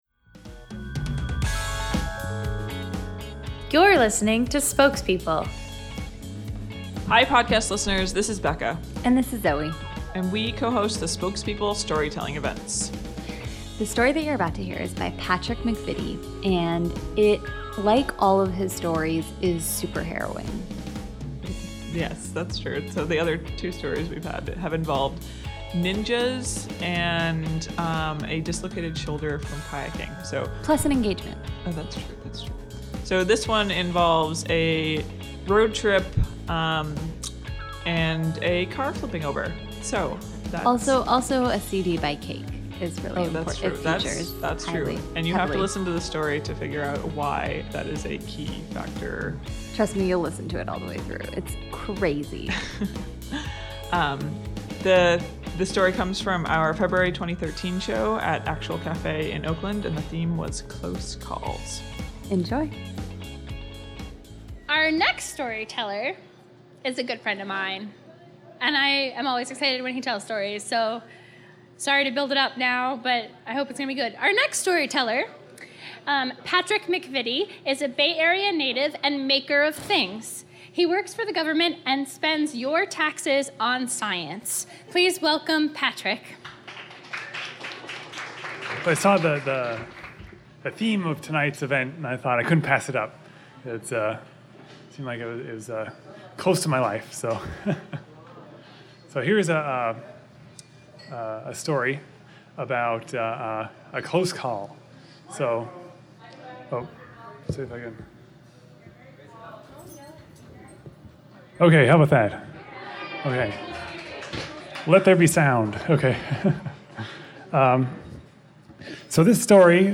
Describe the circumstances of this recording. If you only have one CD — a Cake CD — and it falls to the opposite side of the car, what is the appropriate response at 75 mph? Our storyteller shares his take as well as weighs the pros and cons of hitting on your EMT in this story from our February 2013 show, Close Call.